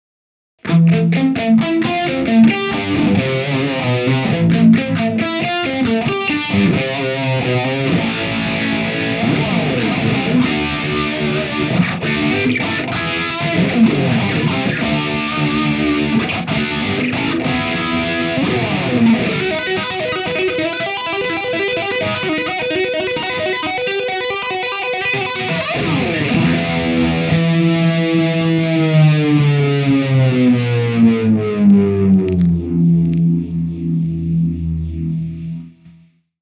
Vintage Phaser
Chitarra: Fender Stratocaster HSS w/Floyd Rose
Gear: Red Badger, Eruption, Cornford Roadhouse 30 (Clean)
Mode: Standard (A)
Speed: 5/10